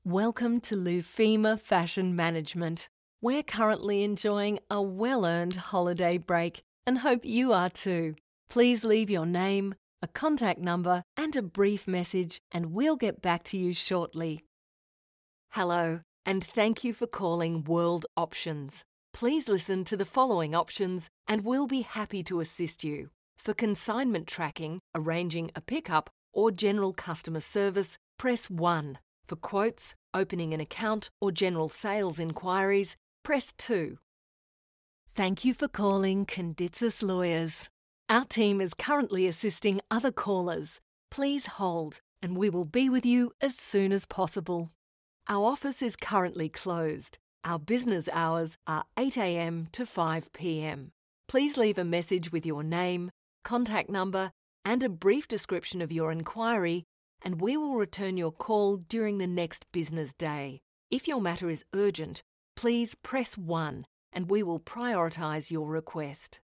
0717Phone_Greetings_and_On_Hold_Messages.mp3